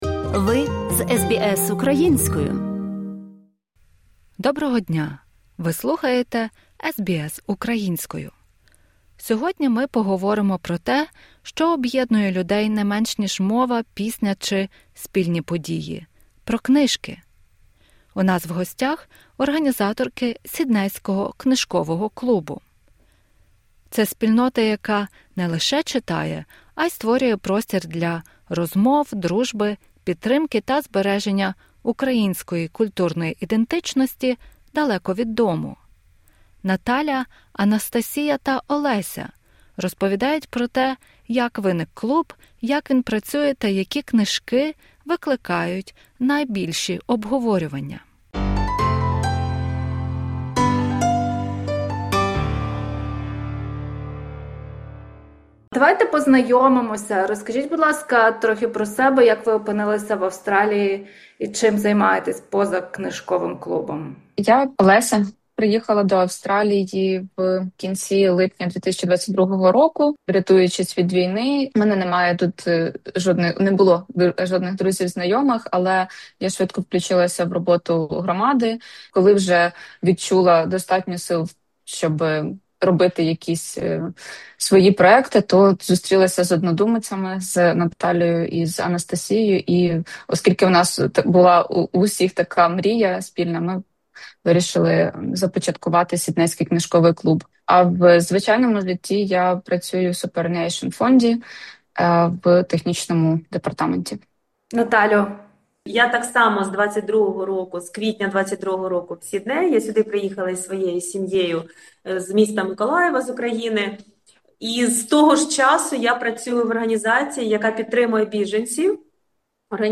У нас в гостях — організаторки Сіднейського книжкового клубу.